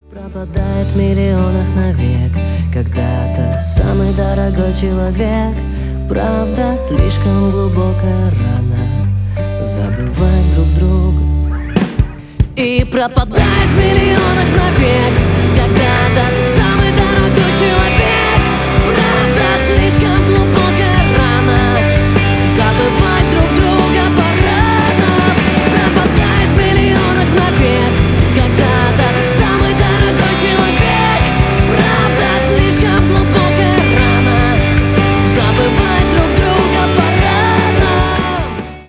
Рок Металл
грустные